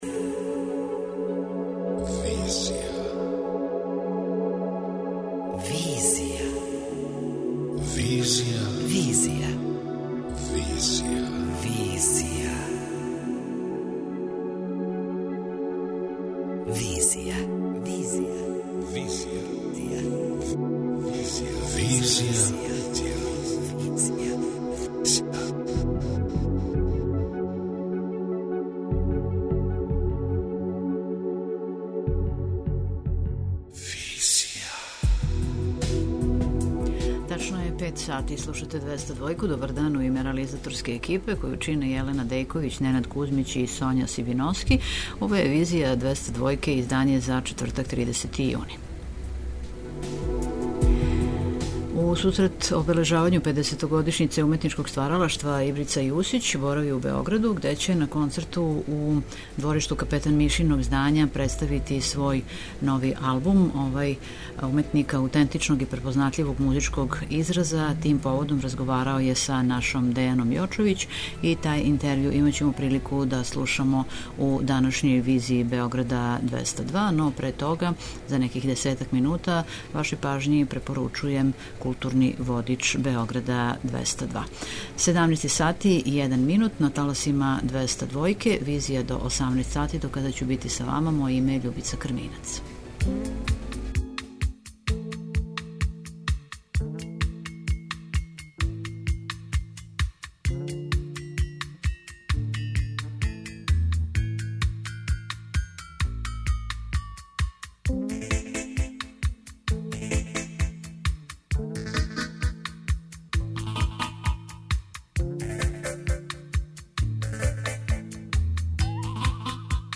Интервју: Ибрица Јусић;